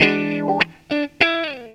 GTR 41 EM.wav